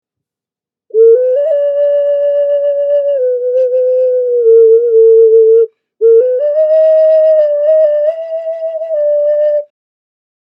Reptile Ocarina Flute Hand Thrown
This Instrument produces a lovely melody.
A recording of the sound of this particular ocarina is in the top description, just click on the play icon to hear the sound.
This musical instrument  is 6.5 inches x 3 inches across and 2 inches tall. It weighs almost 1 pounds, a cord can be added to make into necklace, has 4 key holes